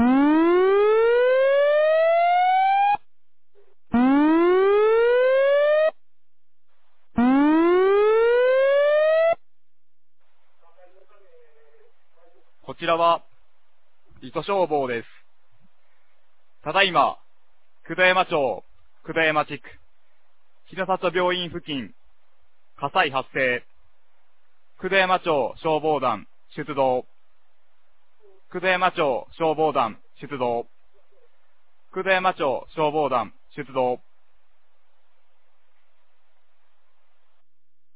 2025年06月05日 09時14分に、九度山町より全地区へ放送がありました。